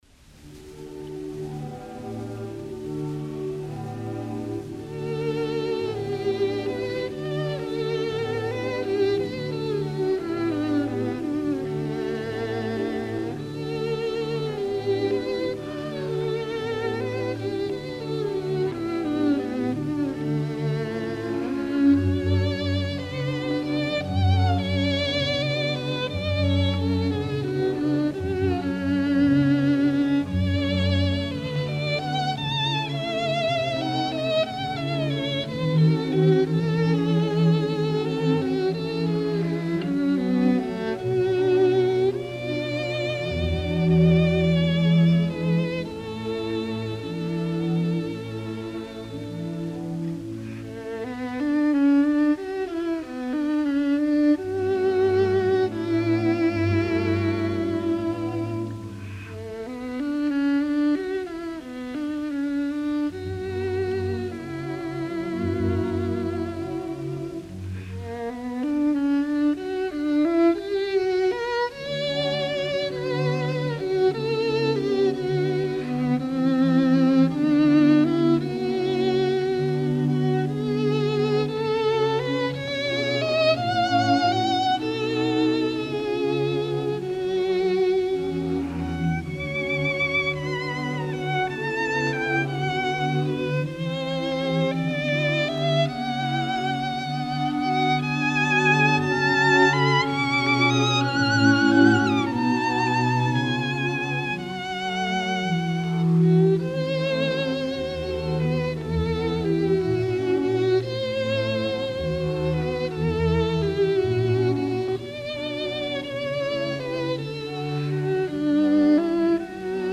It’s usually in triple meter with a very simple tonality – wild and chromatic is just out of the question if you want it to be a lullaby.
Performed by
Piero Coppola
Orchestre des Concerts Pasdeloup
Recorded in 1935